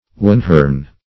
wanhorn - definition of wanhorn - synonyms, pronunciation, spelling from Free Dictionary
Search Result for " wanhorn" : The Collaborative International Dictionary of English v.0.48: Wanhorn \Wan"horn`\, n. [Corruption fr. Siamese wanhom.]